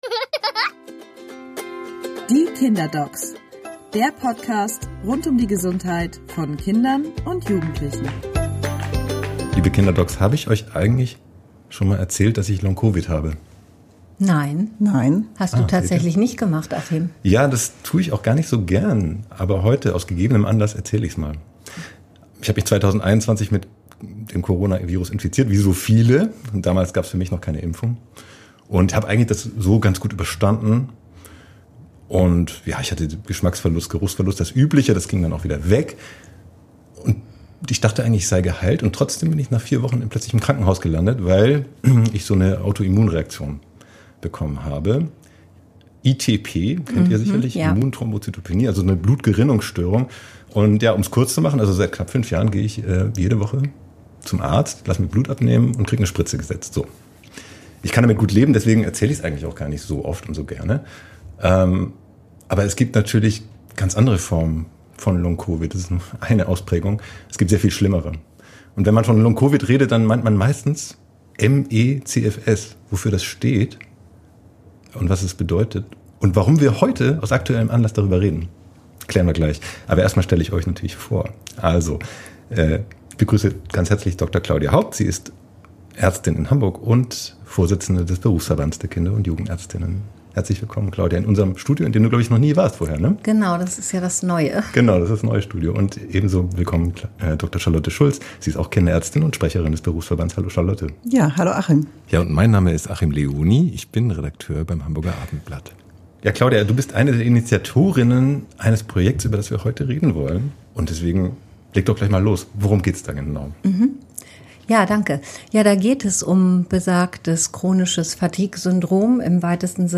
Die Kinderärztinnen